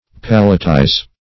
Palatize \Pal"a*tize\, v. t.